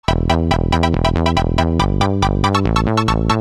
без слов
Короткий звук на смс-ку